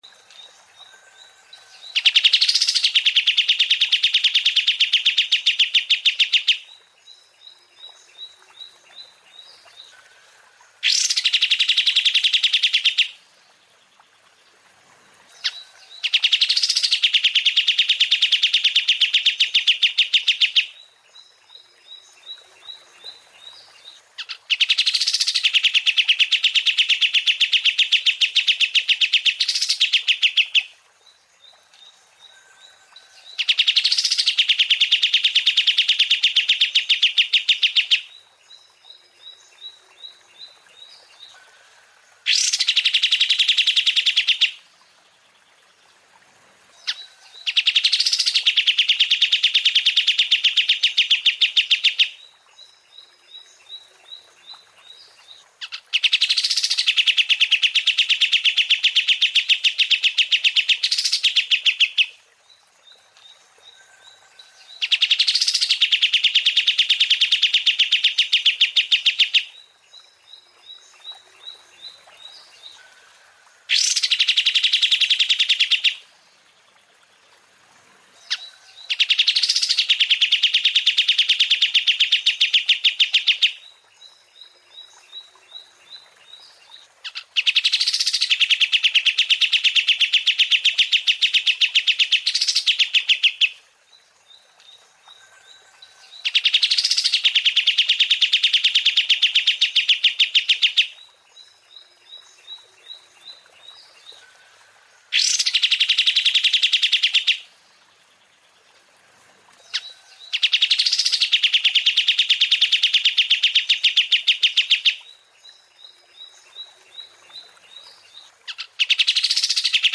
Download suara burung Kapas Tembak gacor durasi panjang MP3, jernih, dan cocok untuk masteran berbagai jenis burung.
Suara burung KAPAS TEMBAK durasi panjang
Suara tembakan tajam dan cepat masuk, siap membuat burung peliharaan Anda lebih gacor.
suara-burung-kapas-tembak-durasi-panjang-id-www_tiengdong_com.mp3